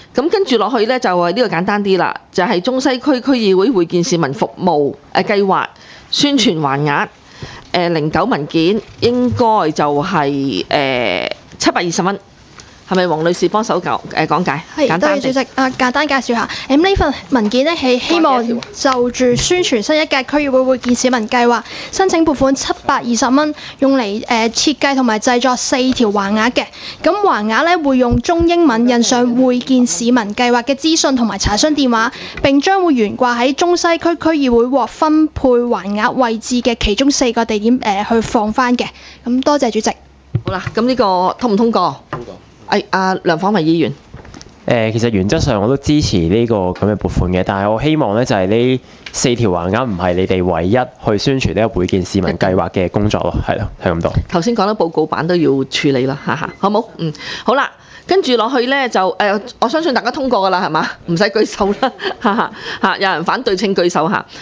区议会大会的录音记录
中西区区议会会议室